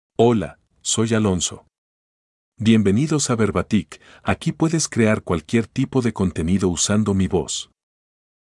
MaleSpanish (United States)
AlonsoMale Spanish AI voice
Voice sample
Male
Alonso delivers clear pronunciation with authentic United States Spanish intonation, making your content sound professionally produced.